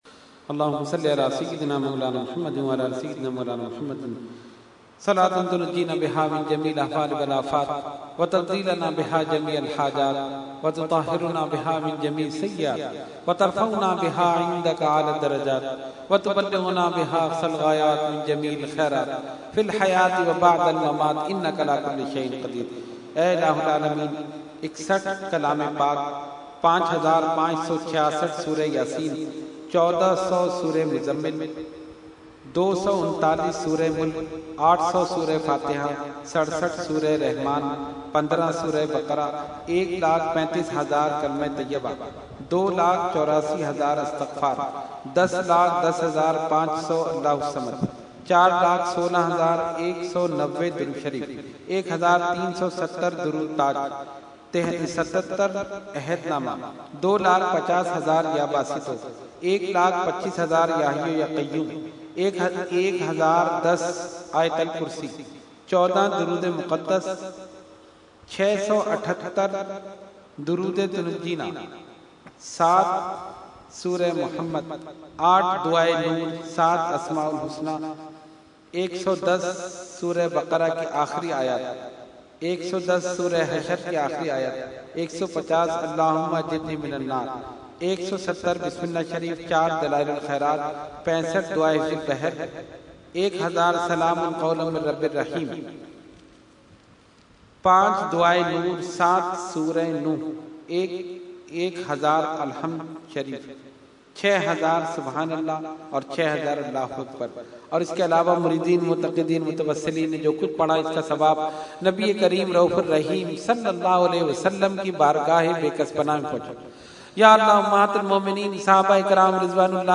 Dua – Urs Ashraful Mashaikh 2015 – Dargah Alia Ashrafia Karachi Pakistan